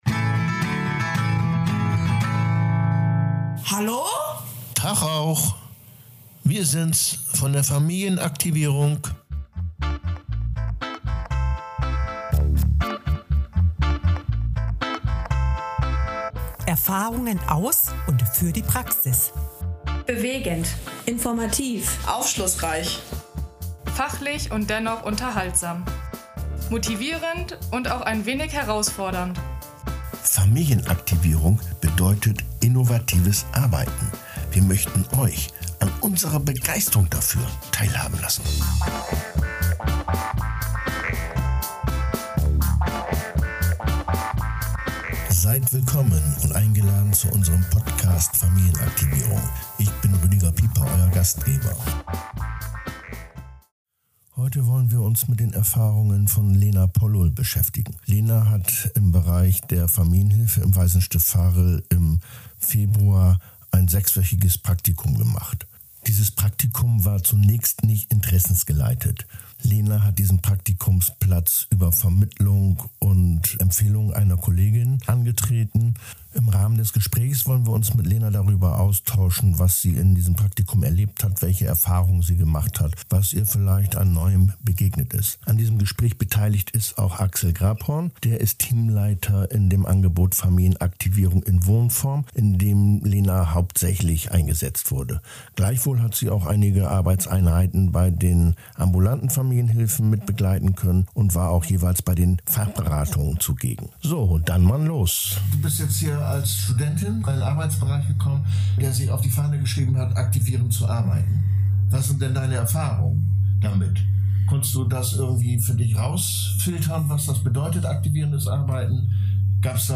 Im Rahmen eines fachlichen Dialoges zwischen Praktikantin und anleitenden Fachkräften wird zurückgeschaut auf das Erleben des Arbeitsbereiches und die im Rahmen eines sechswöchigen Praktikums getätigten Erfahrungen